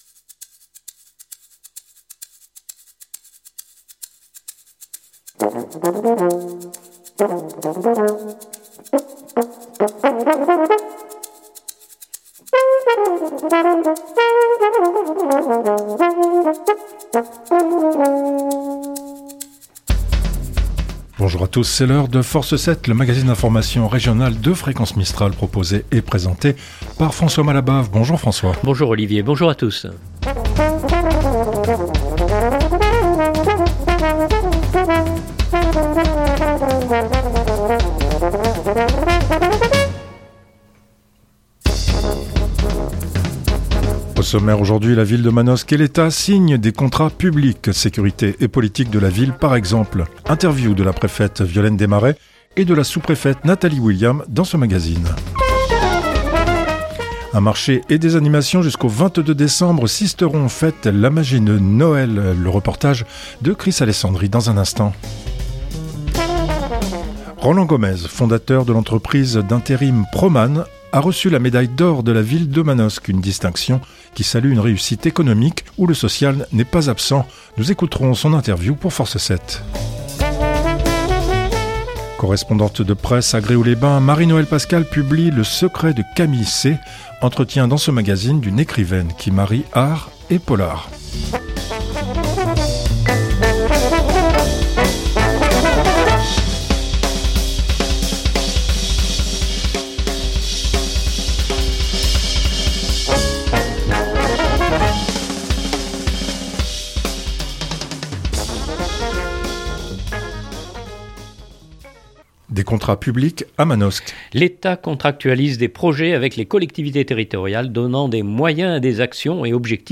Interviews de la préfète Violaine Démaret et de la sous-préfète Natalie William dans ce magazine.